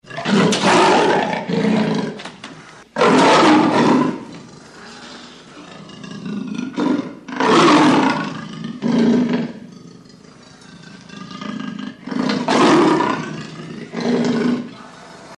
Звуки льва, тигра и кошек